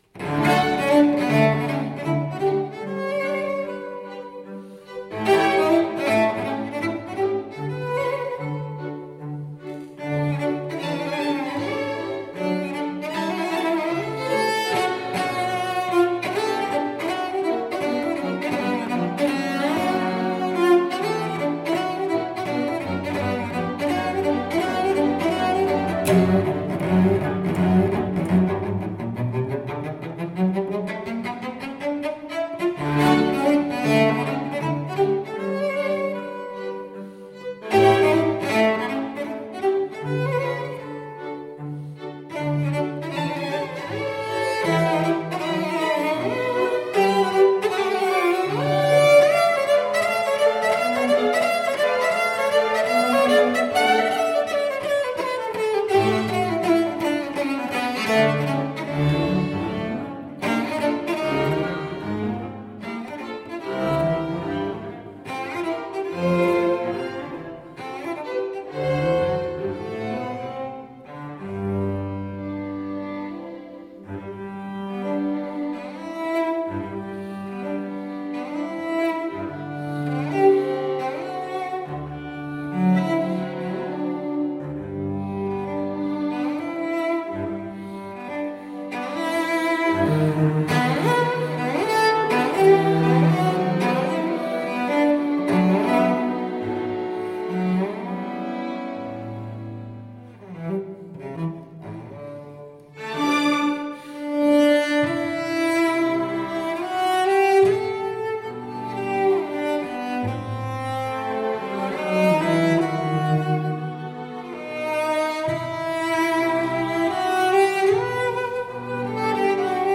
per violoncello e quartetto d'archi
Classical, Romantic Era, Instrumental, Orchestral, Cello